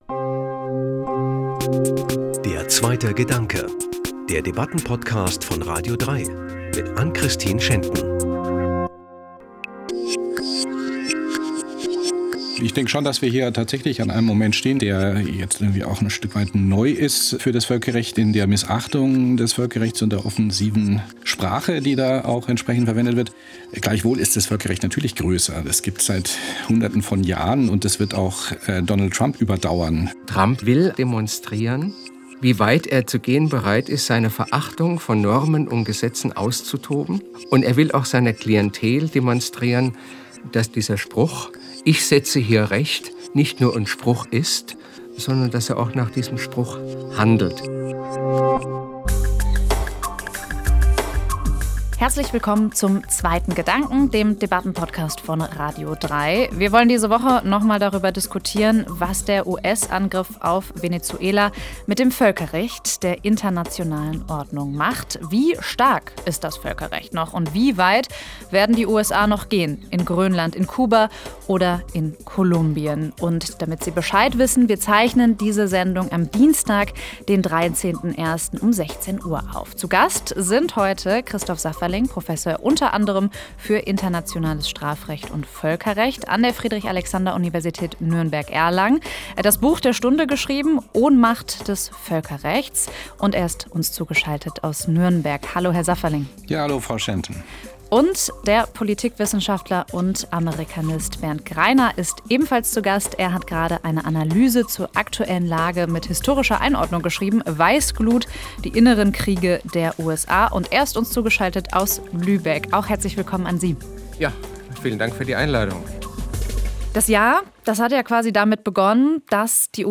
Die Debatte